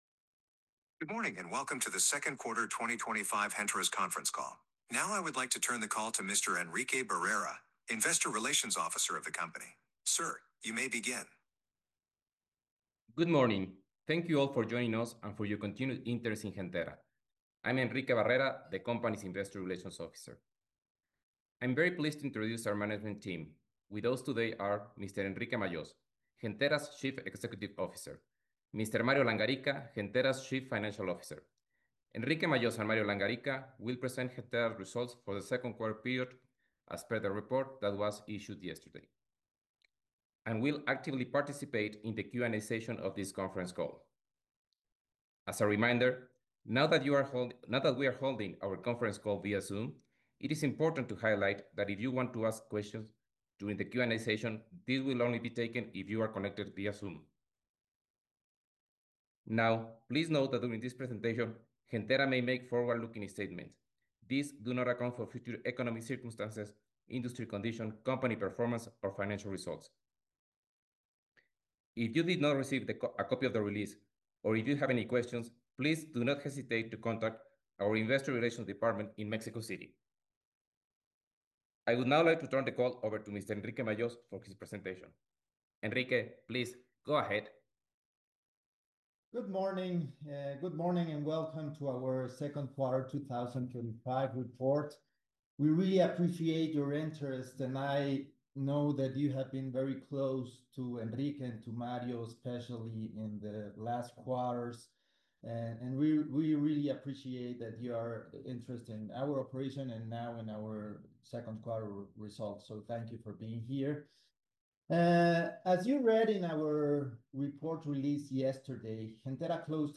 Reporte trimestral y conferencia de resultados